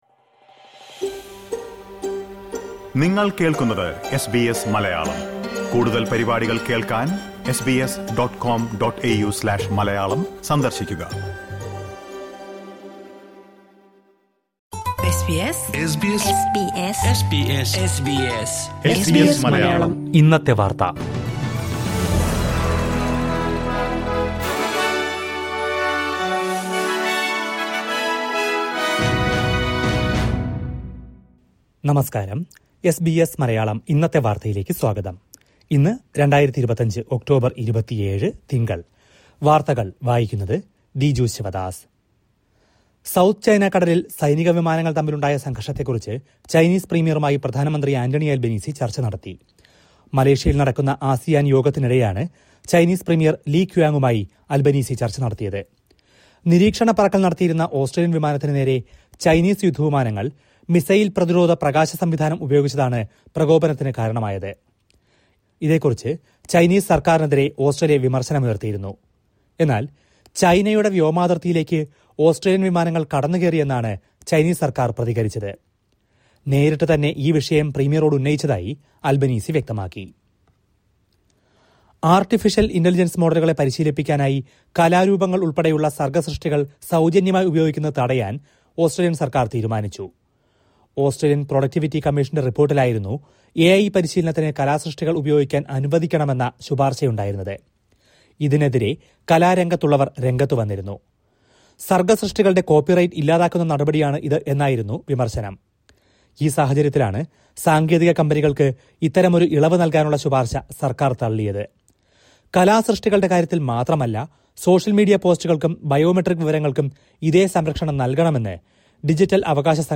2025 ഒക്ടോബർ 27ലെ ഓസ്ട്രേലിയയിലെ ഏറ്റവും പ്രധാന വാർത്തകൾ കേൾക്കാം...